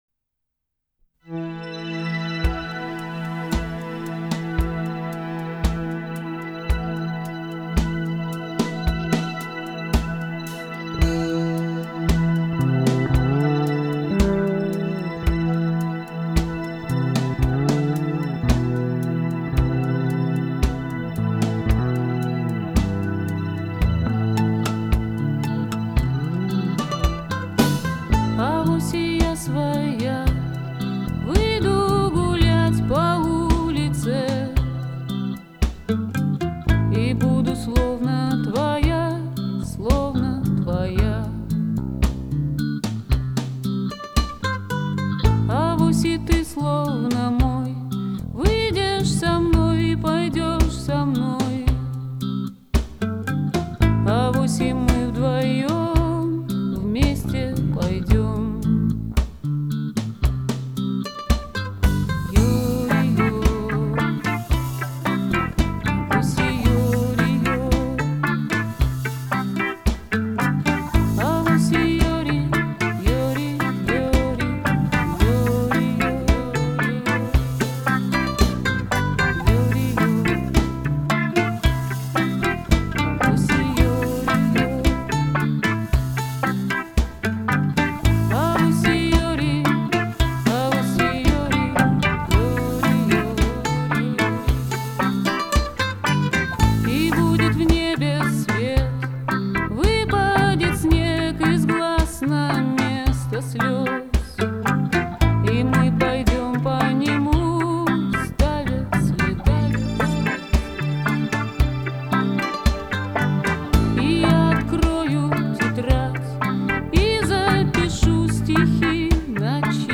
Студийный электрический альбом.
гитара, мандолина
бас-гитара
барабаны, перкуссия, голос